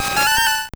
Cri de Girafarig dans Pokémon Or et Argent.